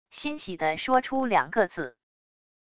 欣喜地说出两个字――wav音频